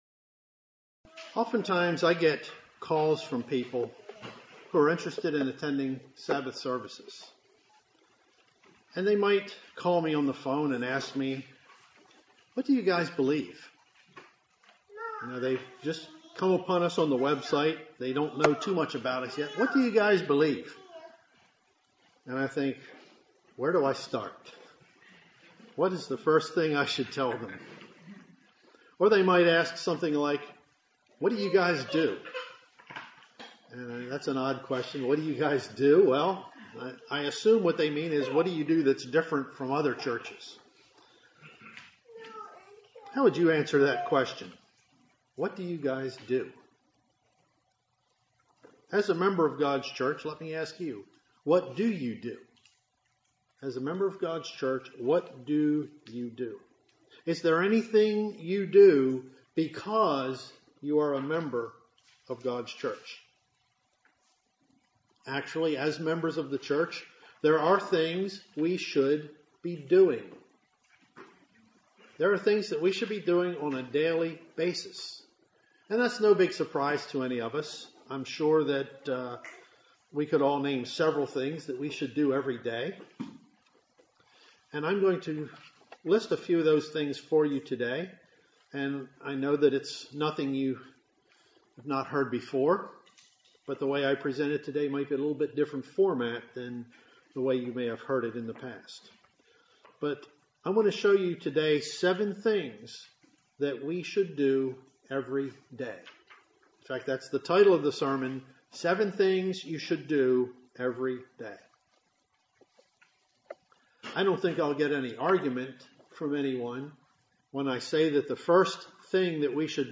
Given in Columbia, MD
UCG Sermon Studying the bible?